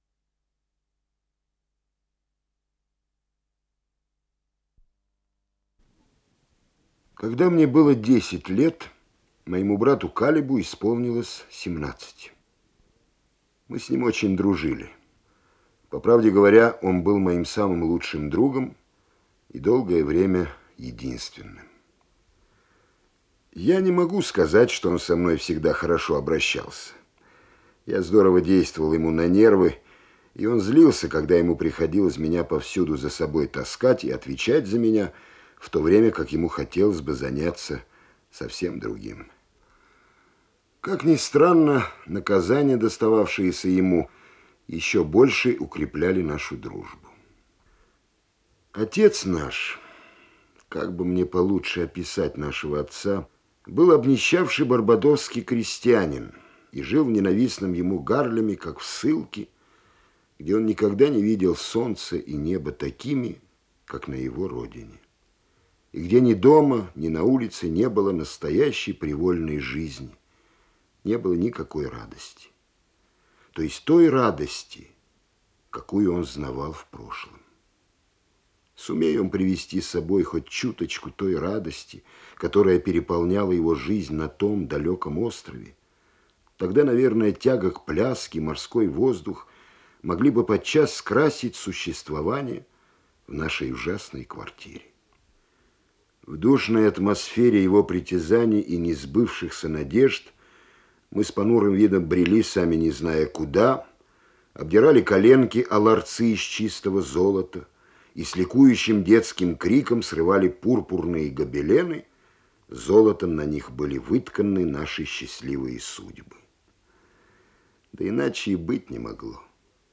Исполнитель: Армен Джигарханян - чтение